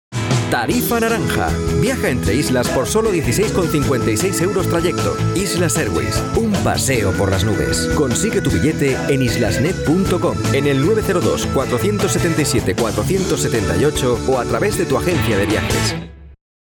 Commercieel, Natuurlijk, Veelzijdig, Vertrouwd, Zakelijk
Commercieel